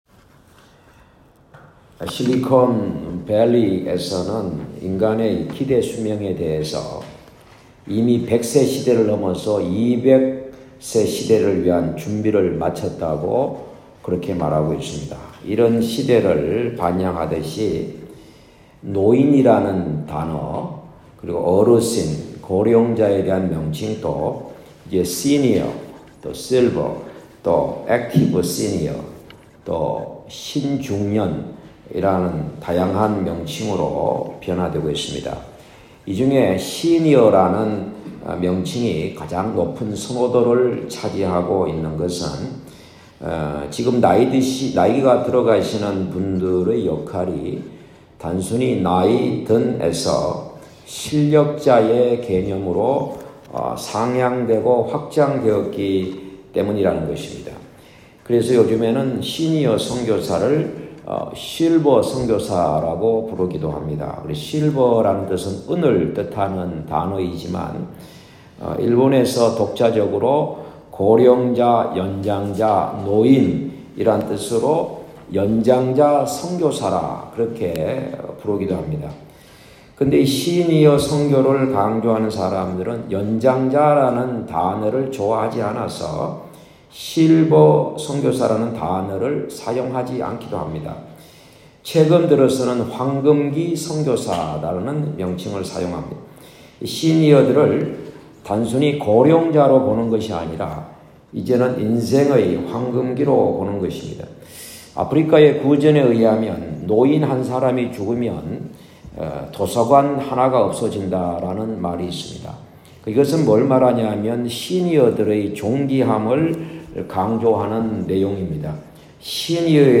2024년 10월 6일 주일설교(1부 오전 9시